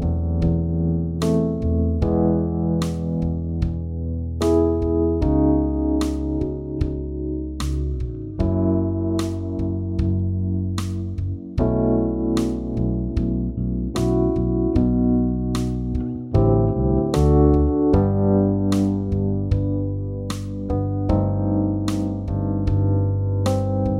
no Backing Vocals Soul / Motown 5:38 Buy £1.50